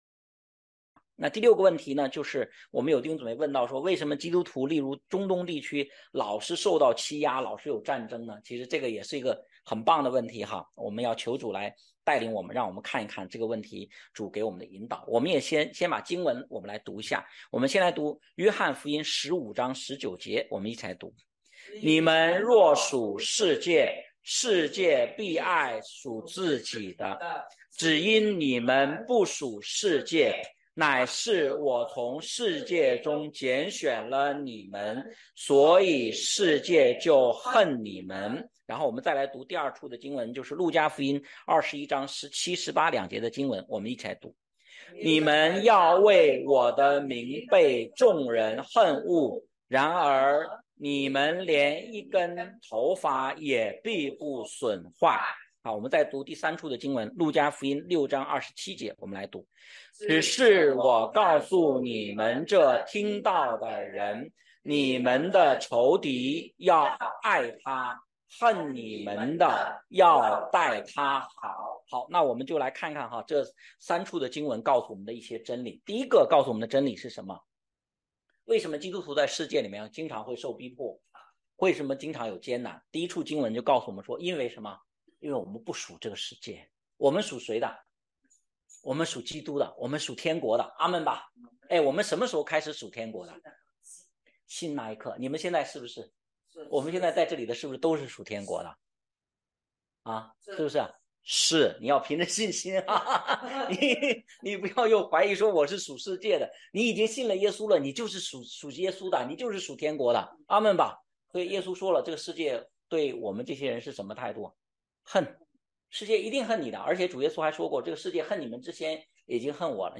问题解答录音